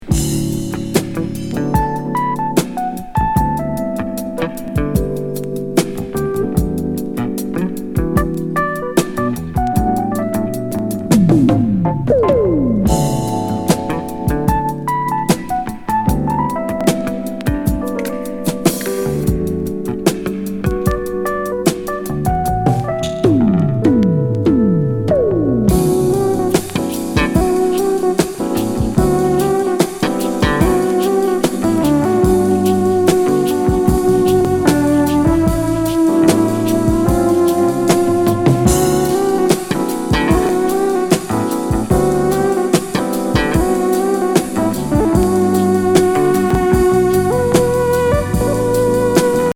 メロウ♪